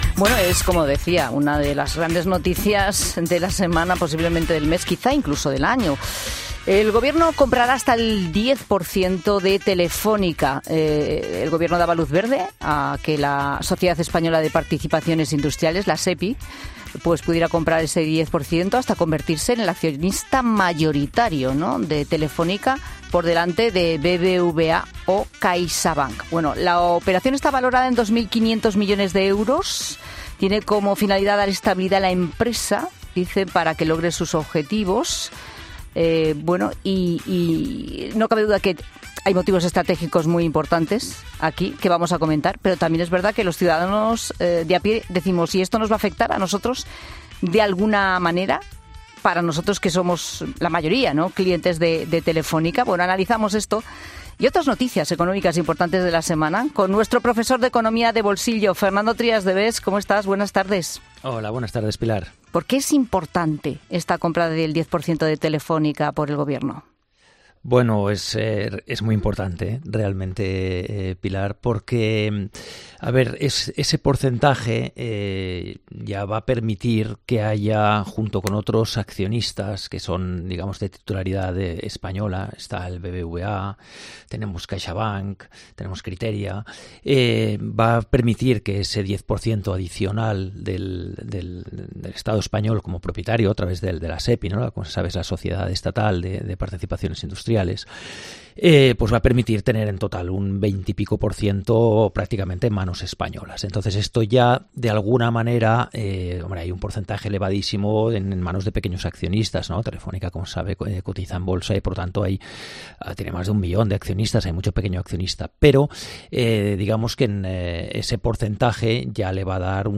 El economista Fernando Trías de Bes explica en La Tarde cómo afecta a los ciudadanos este movimiento y cuáles son todos los cambios tras la reforma del subsidio por desempleo